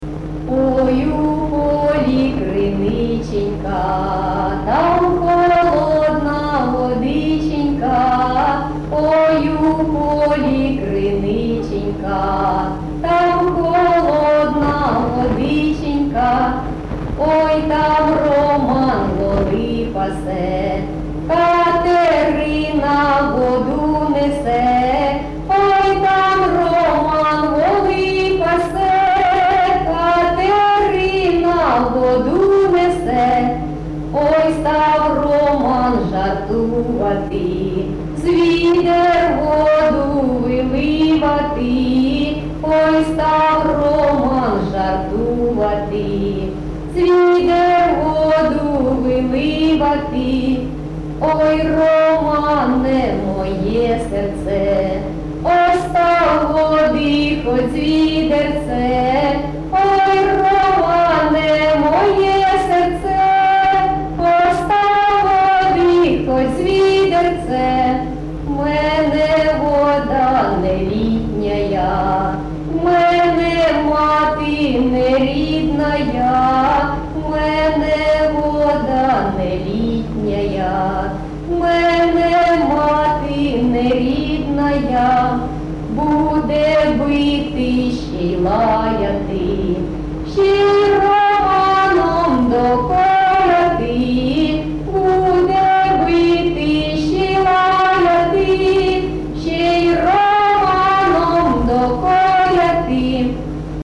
ЖанрПісні з особистого та родинного життя
Місце записус. Євсуг, Старобільський район, Луганська обл., Україна, Слобожанщина